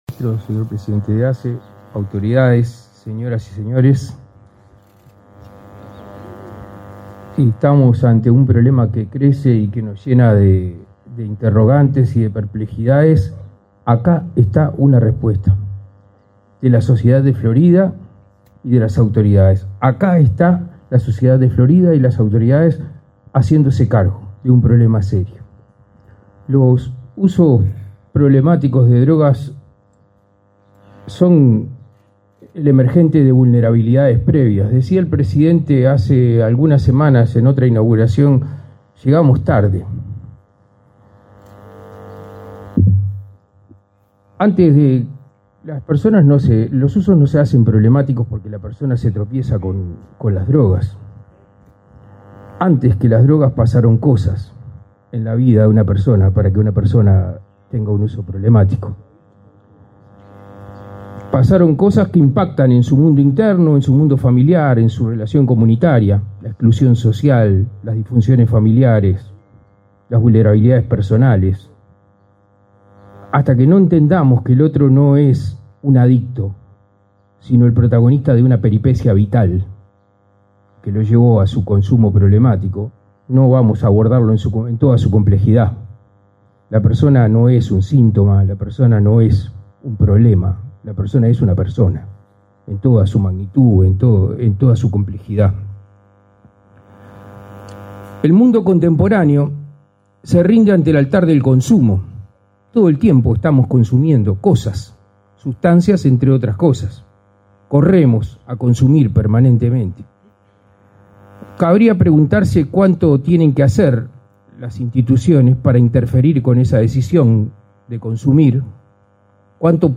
Palabras del presidente de ASSE, Leonardo Cipriani, y del director de la JND, Daniel Radío
Palabras del presidente de ASSE, Leonardo Cipriani, y del director de la JND, Daniel Radío 27/09/2023 Compartir Facebook X Copiar enlace WhatsApp LinkedIn Con la presencia del presidente de la República, Luis Lacalle Pou, fue inaugurado, este 27 de setiembre, el centro diurno para personas adictas en la ciudad de Florida. Participaron de la actividad el presidente de la Administración de los Servicios de Salud del Estado, Leonardo Cipriani, y el director de la Junta Nacional de Drogas, Daniel Radío.